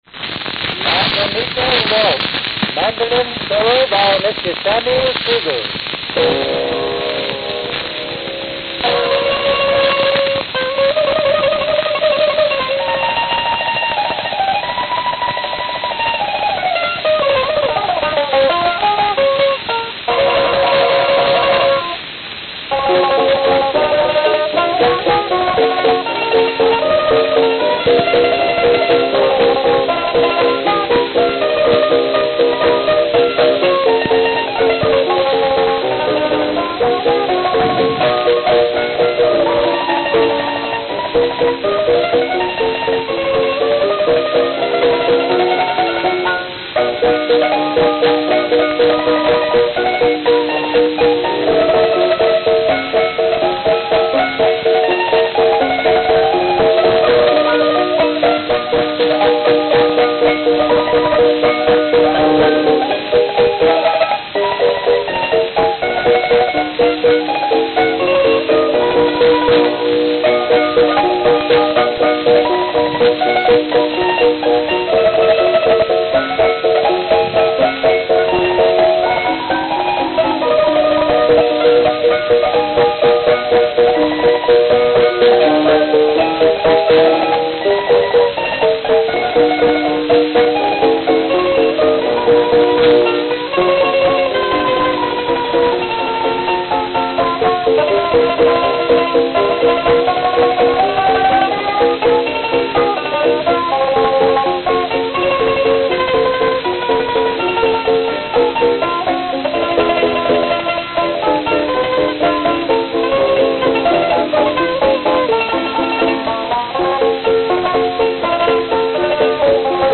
Camden, New Jersey (?)
Note: Announced. Worn, especially at start.